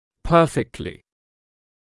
[‘pɜːfɪktlɪ][‘пёːфиктли]отлично, прекрасно; в полной мере